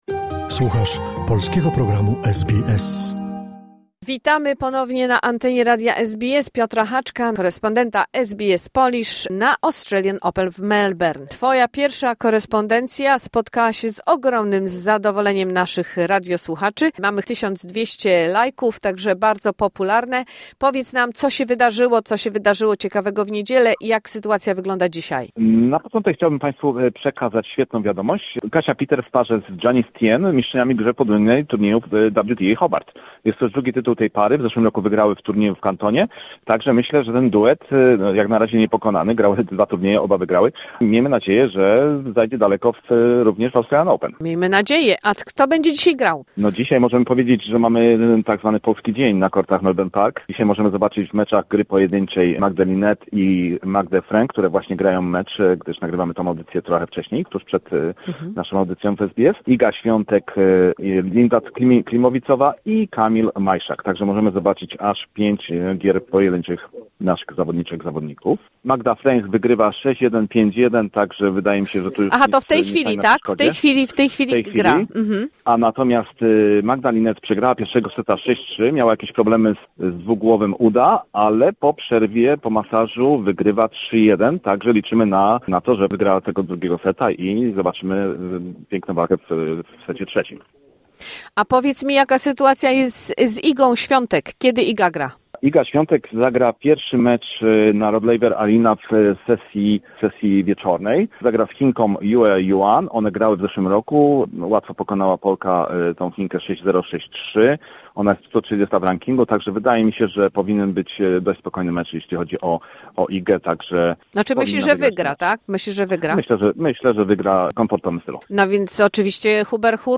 Nasz korespondent